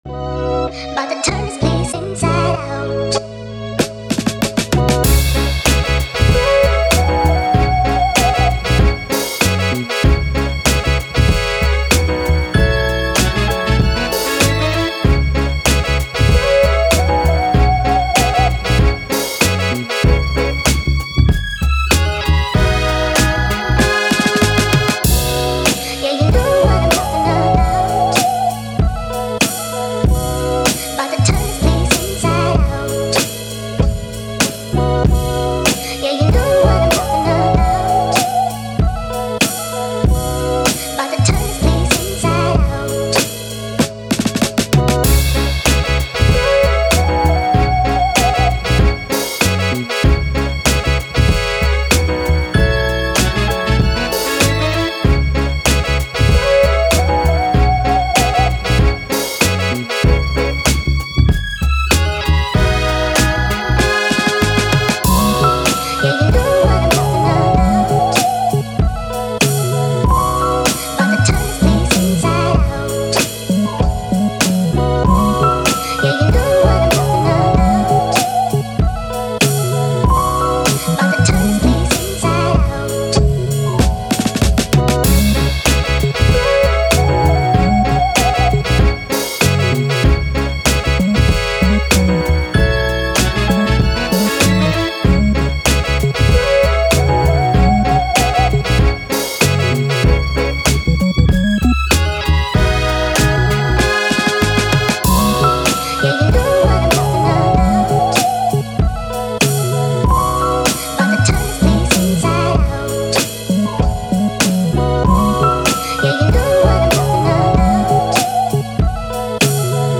Hip Hop, Boom Bap, Positive